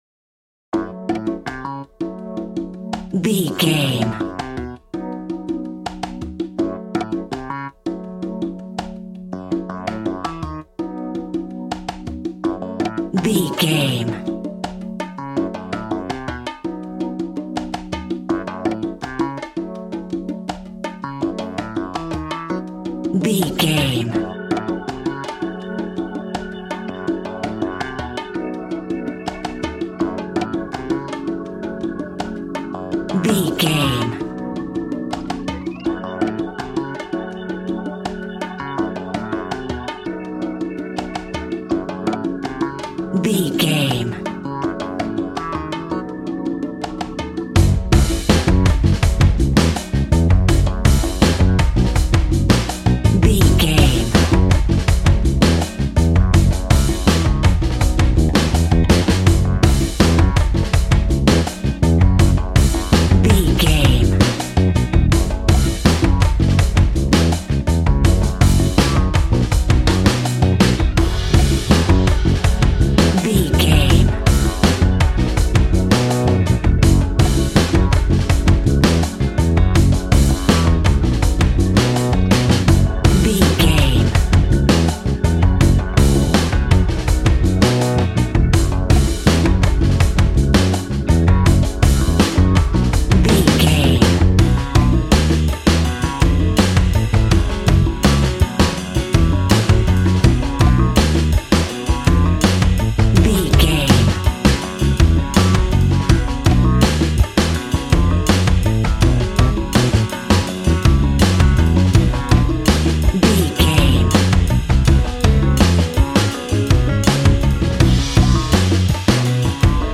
Aeolian/Minor
G♭
relaxed
smooth
synthesiser
drums
80s